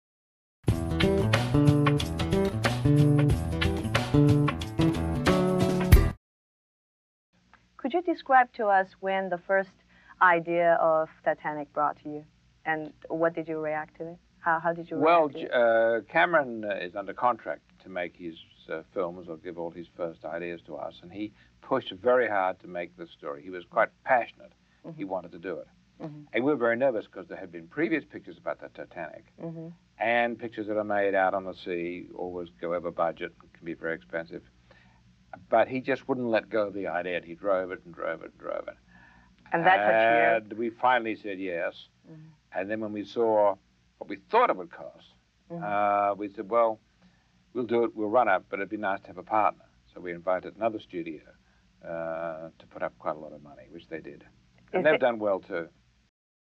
名人名篇-杨澜专访 听力文件下载—在线英语听力室